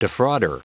Prononciation du mot defrauder en anglais (fichier audio)
Prononciation du mot : defrauder